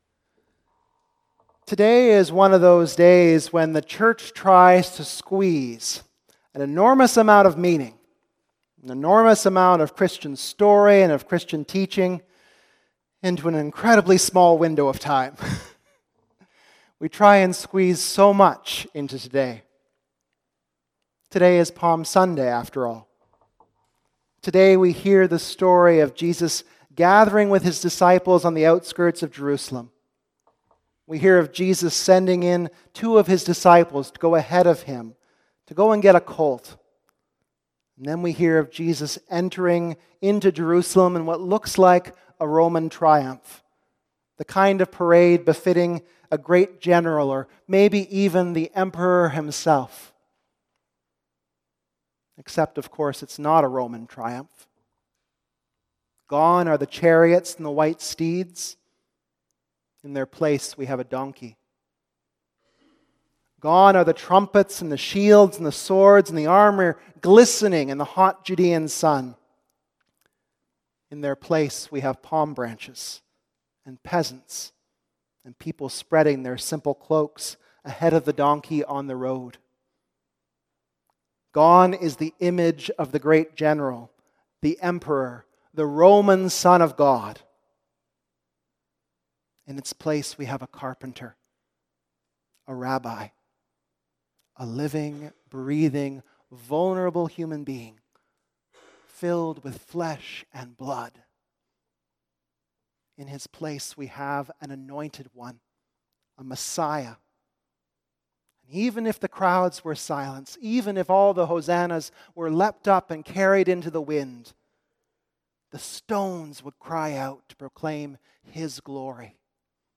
Preached at Holy Trinity Pembroke.